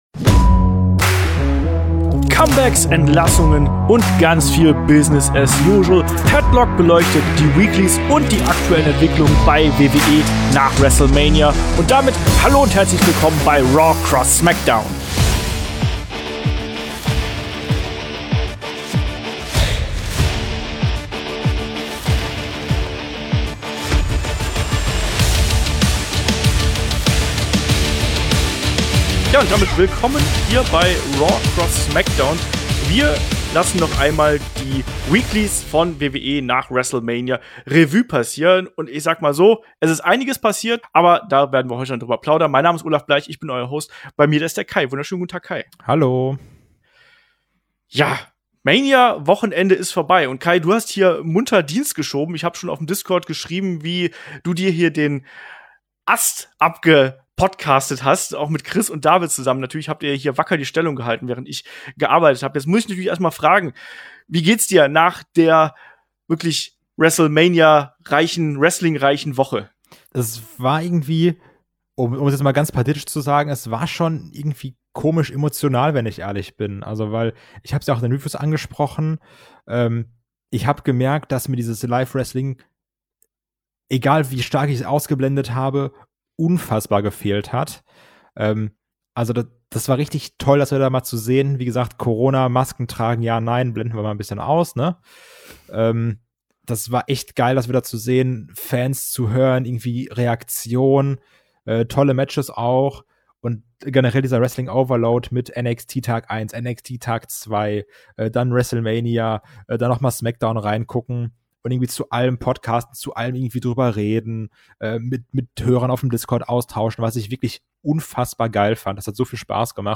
Die beiden nehmen für euch RAW und SmackDown auseinander, besprechen nach DAS MAGAZIN noch einmal die Informationslage zur Entlassungswelle und diskutieren darüber, wie WWE so kurz nach Wrestlemania schon wieder der Alltag einkehrt.